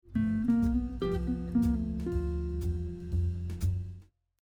This pattern creates a classic blues style lick.
Blues lick using 6ths